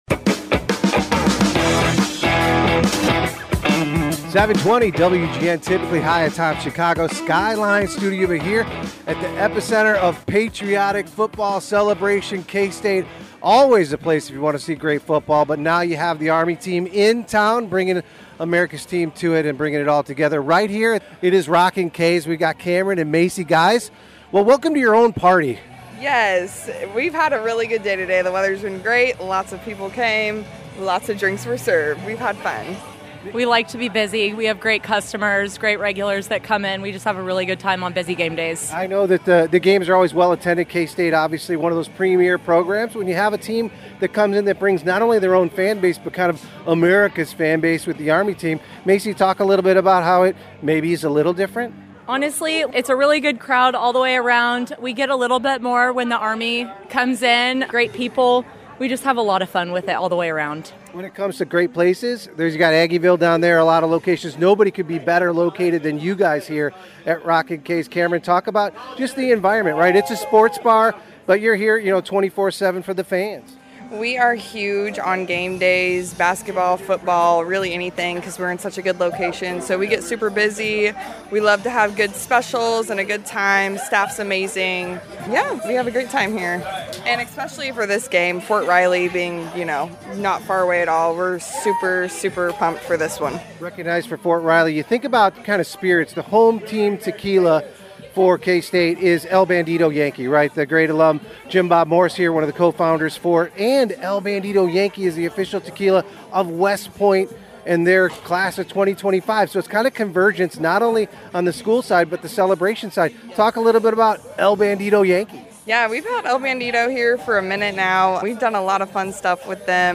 during a pregame celebration and “Tequila Tailgate”.